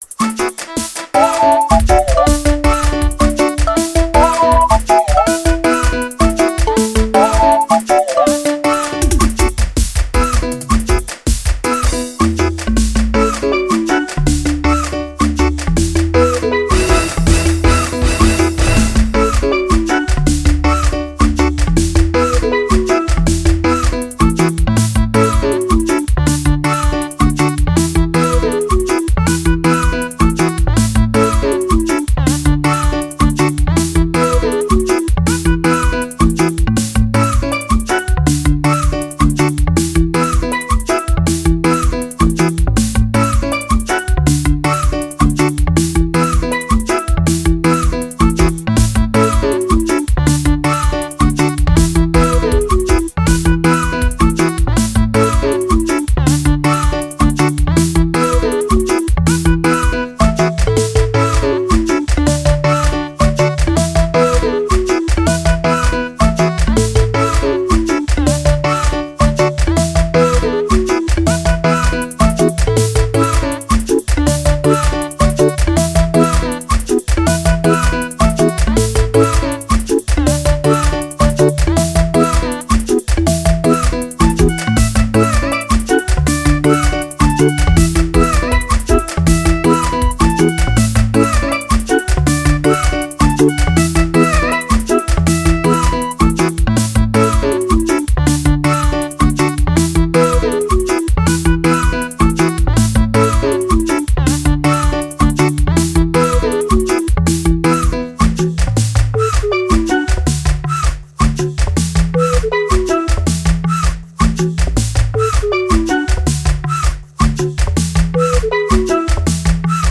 06:00 Genre : Gospel Size